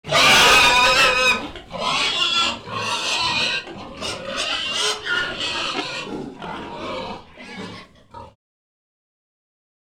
PIG VERY 01L.wav